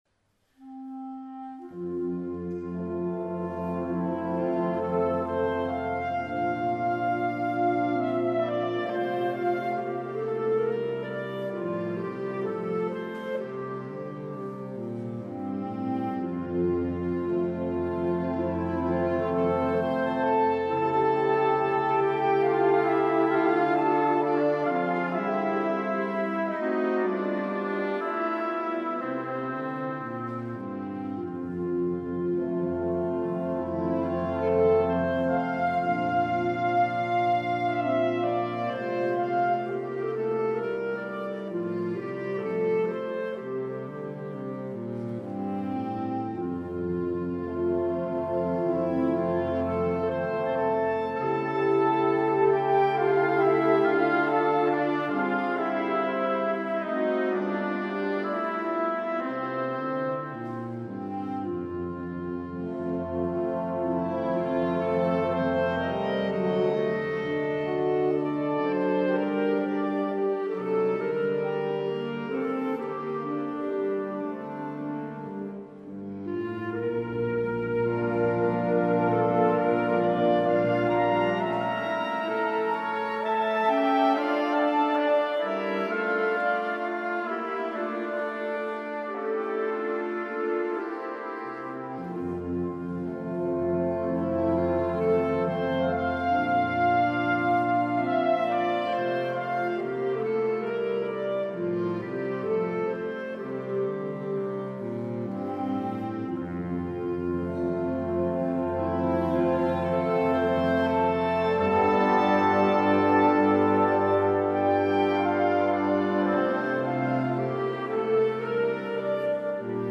registrato dal vivo: Verona 28/3/2010